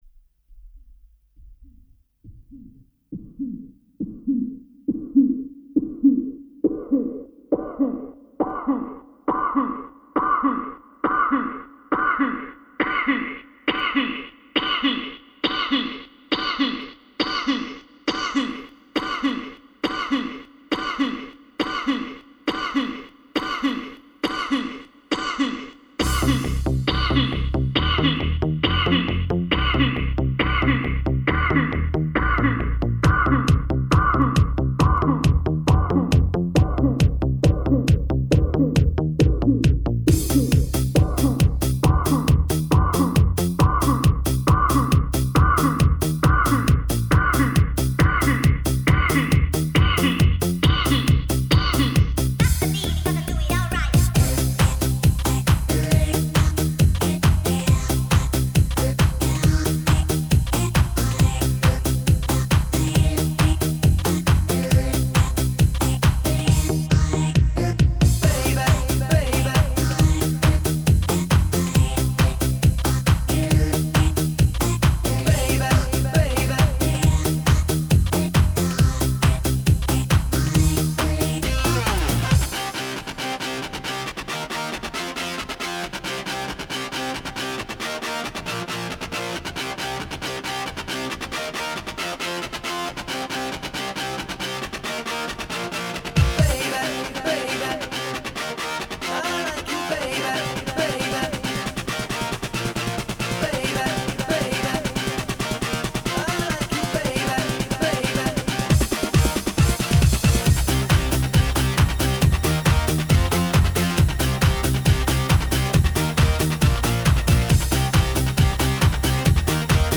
вокал
клавишные.